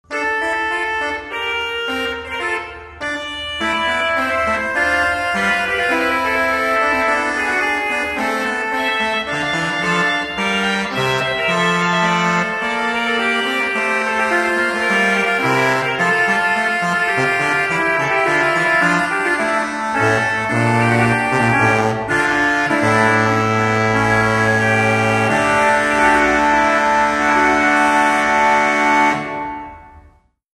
Baroque, Classical, Orchestral, Organ